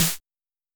S 78_Snare.wav